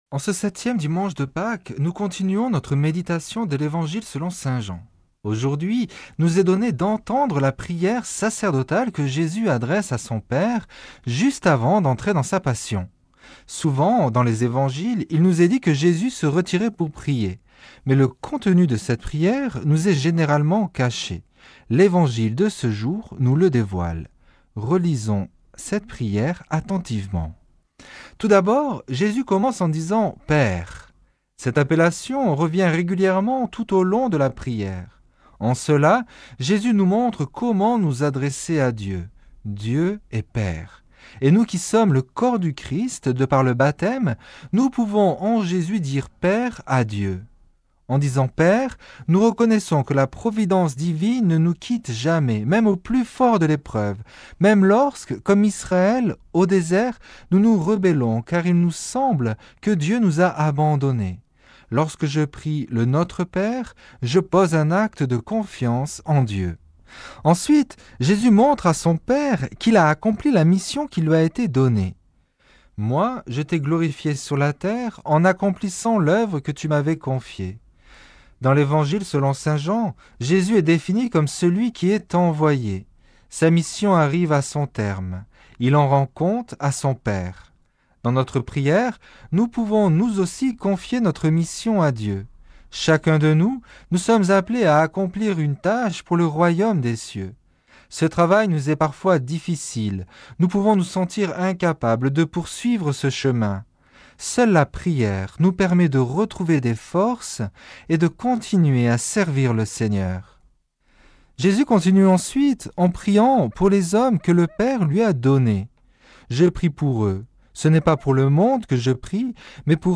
Commentaire de l'Evangile du 1er juin